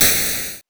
8 bits Elements
explosion_18.wav